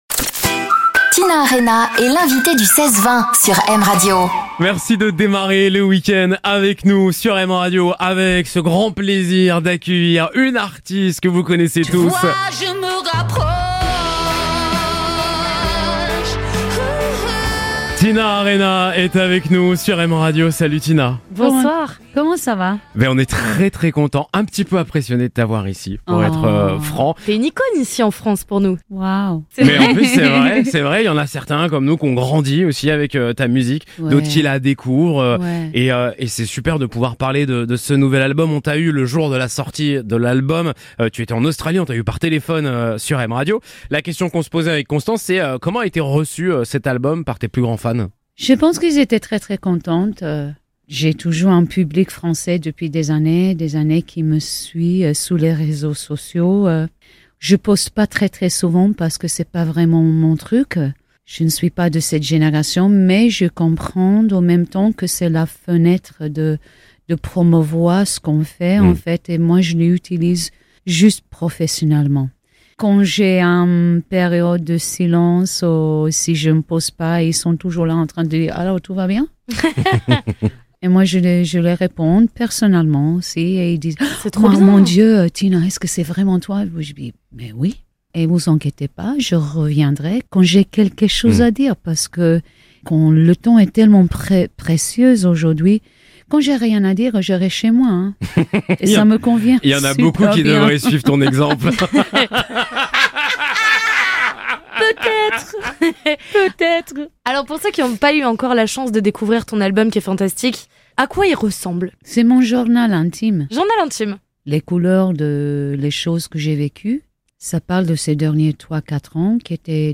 Tina Arena est l'invitée du 16/20 M Radio